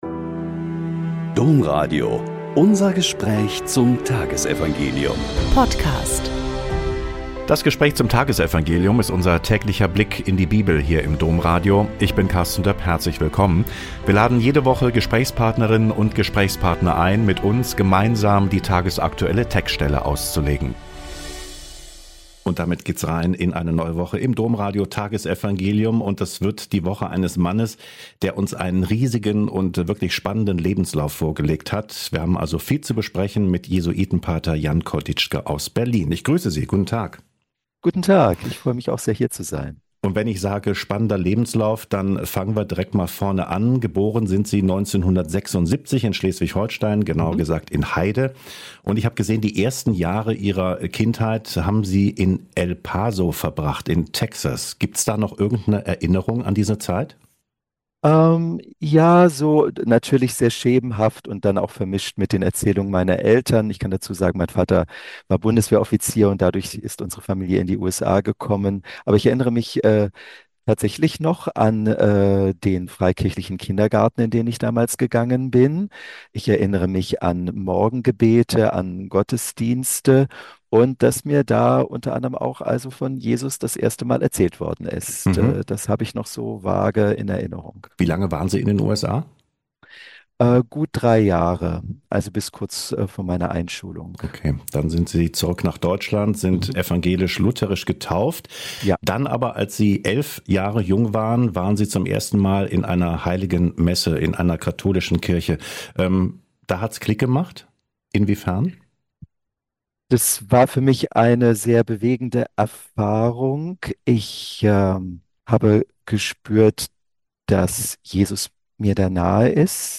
Mk 8,11-13 - Gespräch